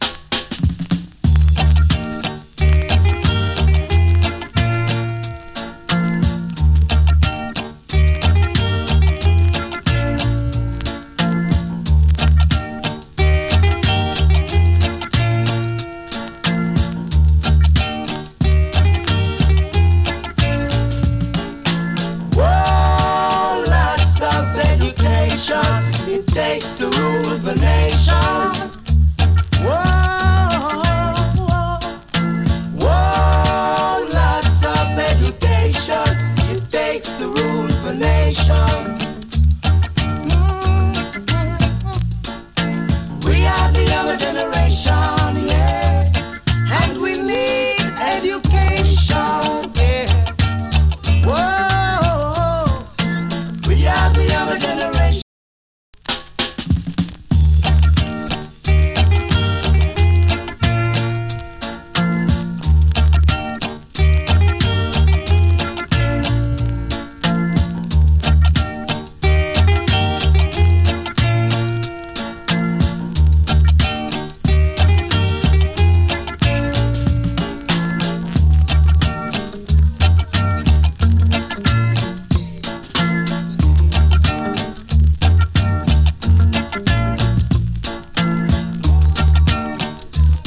チリ、パチノイズ少々有り。
NICE ROOTS VOCAL EARLY REGGAE !